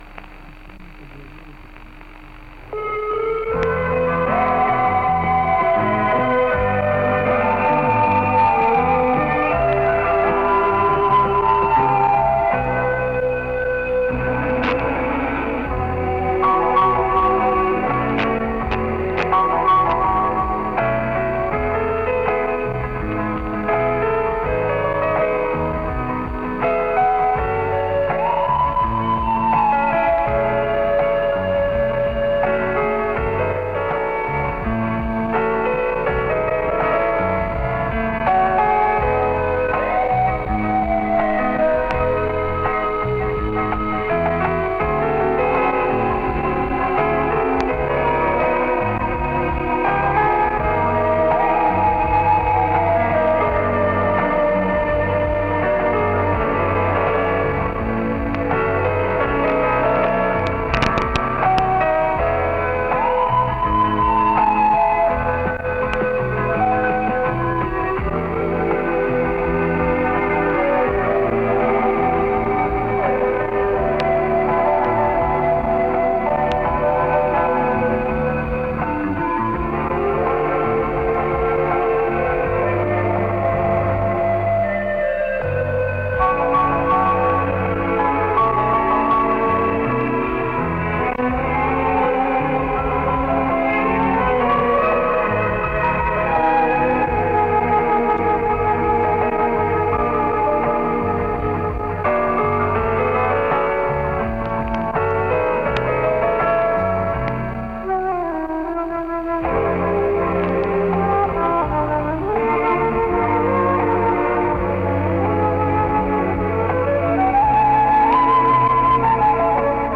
югославский кларнетист и клавишник